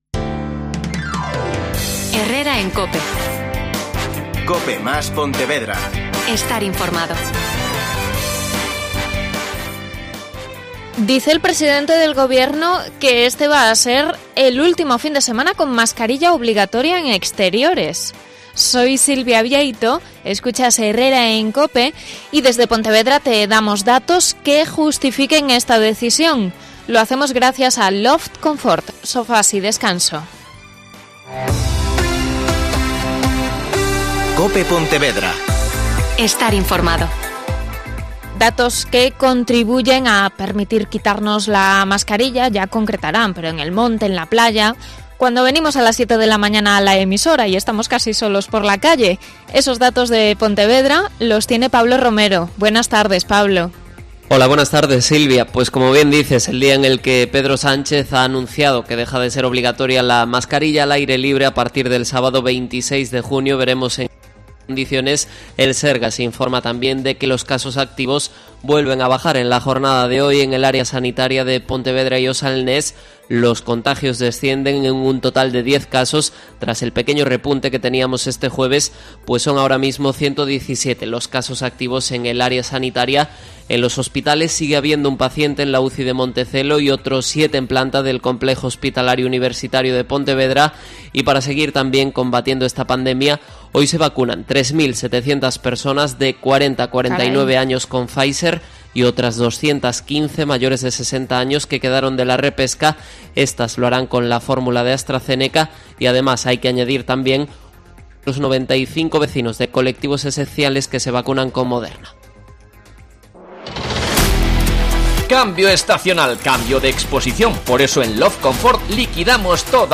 Redacción digital Madrid - Publicado el 18 jun 2021, 13:11 - Actualizado 17 mar 2023, 21:03 1 min lectura Descargar Facebook Twitter Whatsapp Telegram Enviar por email Copiar enlace José Cacabelos. Alcalde de O Grove.